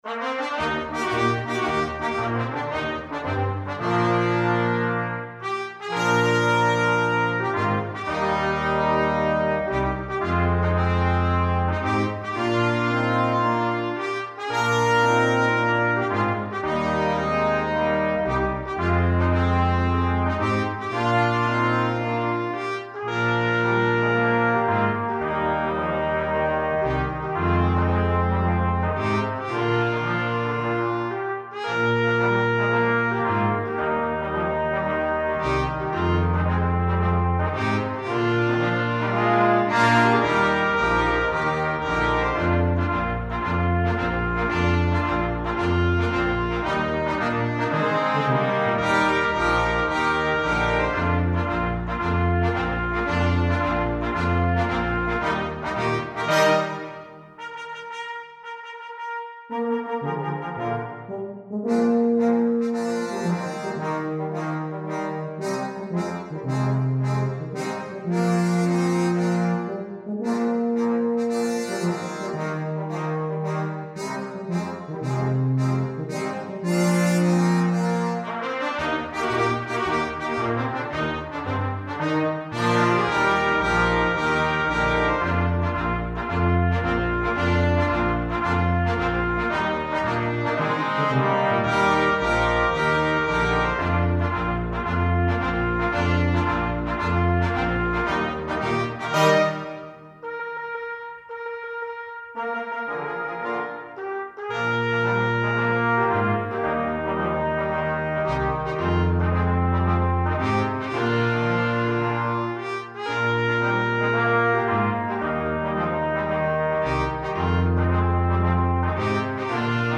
Gattung: für Blechbläser Quintett